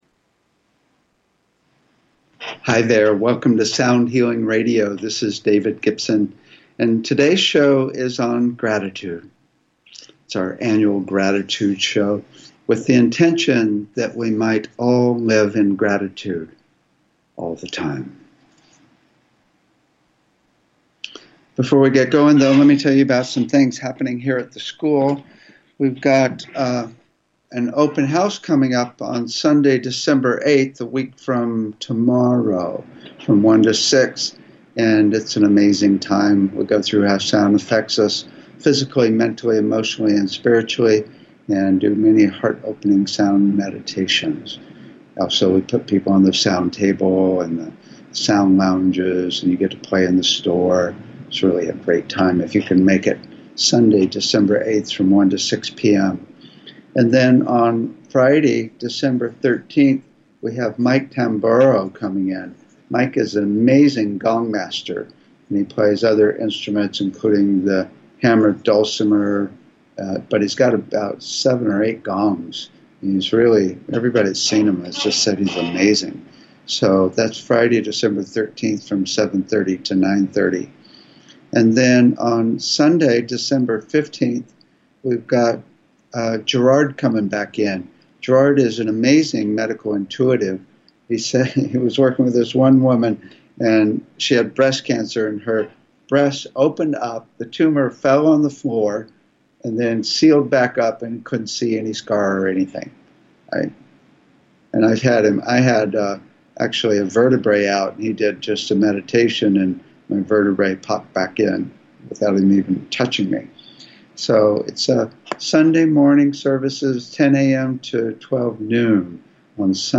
Talk Show Episode, Audio Podcast, Sound Healing and Gratitude, with the intention that we all live in gratitude all of the time on , show guests , about gratitude,with the intention,live in gratitude,all of the time, categorized as Health & Lifestyle,Sound Healing,Kids & Family,Philosophy,Physics & Metaphysics,Science,Self Help,Spiritual,Technology